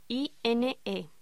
Locución: INE